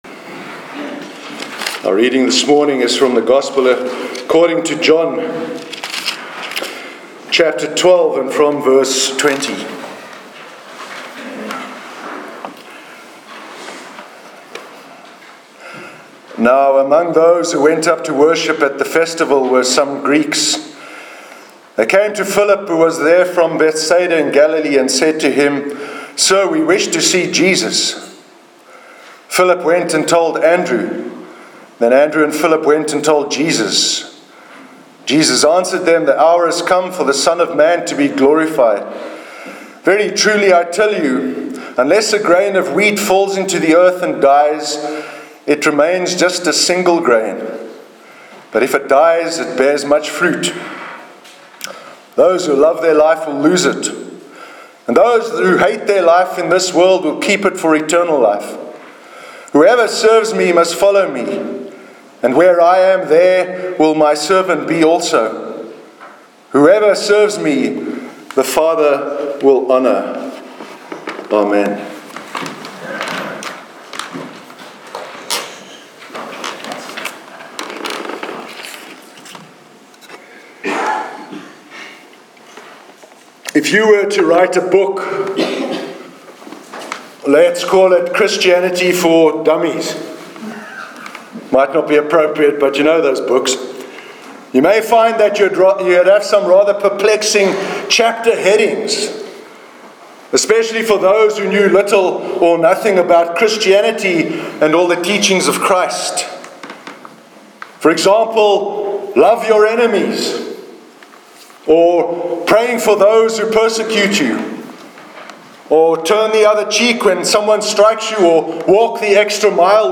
Sermon on Dying to Self- 18th June 2017